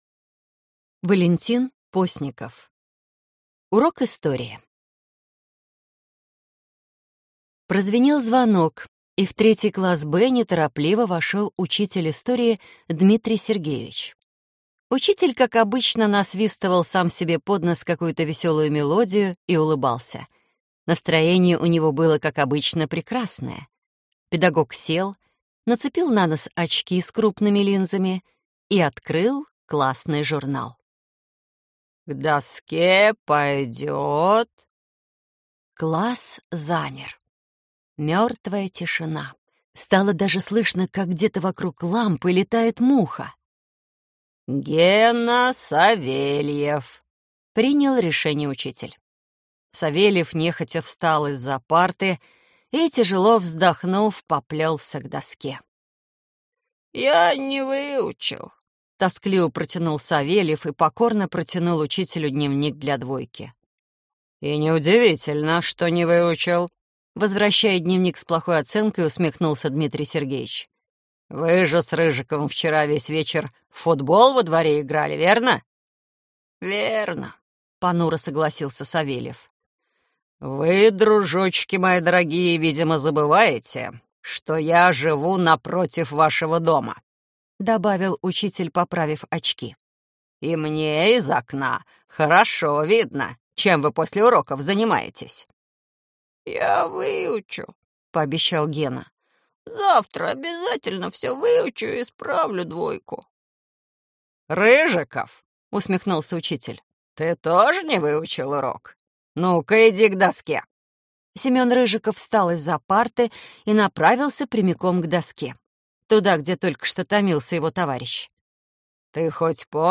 Аудиокнига Кастрюля с пятёрками | Библиотека аудиокниг